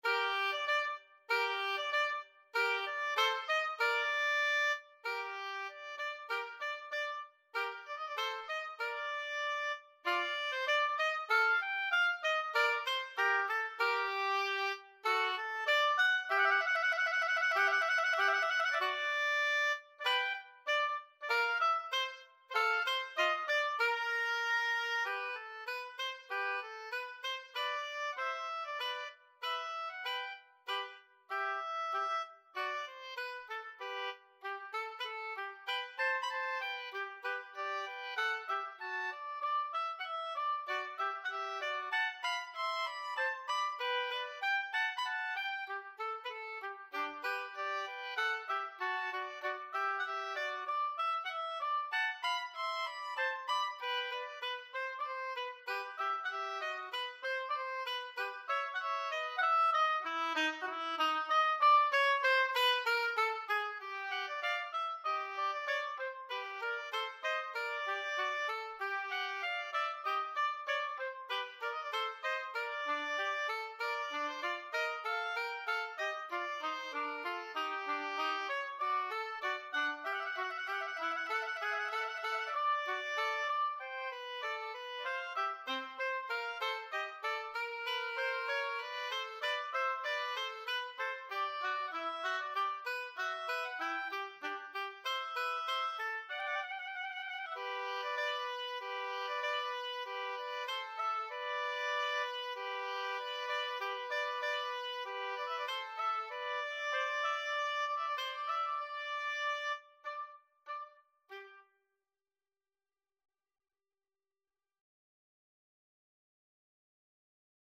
2/4 (View more 2/4 Music)
Allegro moderato =96 (View more music marked Allegro)
Oboe Duet  (View more Intermediate Oboe Duet Music)
Classical (View more Classical Oboe Duet Music)